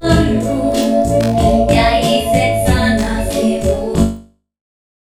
Chorus/flanger
White chorus: delay 20 ms, modulation frequency 0.3 Hz, modulation depth 10 ms, feedback 0.7071, feedforward 1, blend 0.7071